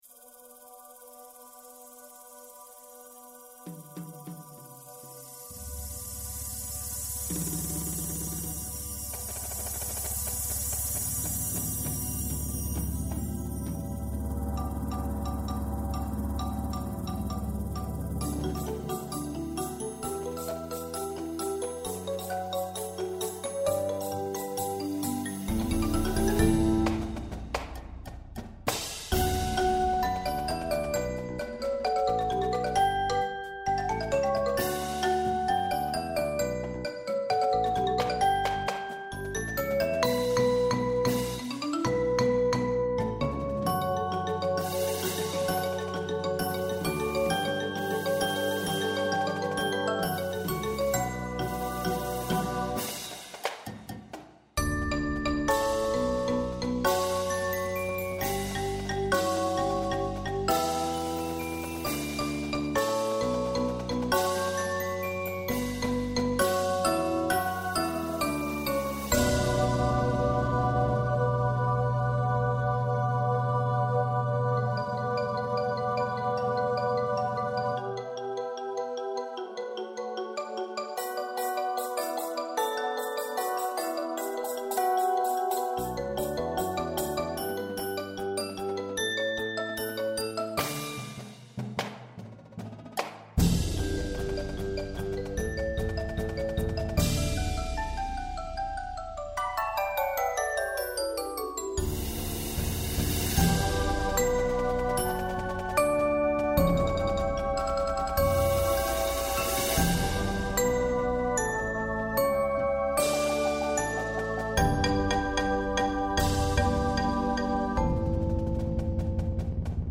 Snares
Tenors, quints
5 Basses
2 Marimbas
2 Vibraphones
Xylophone
Timpani
Bass Guitar
2 Synthesizer/ Auxiliary Percussion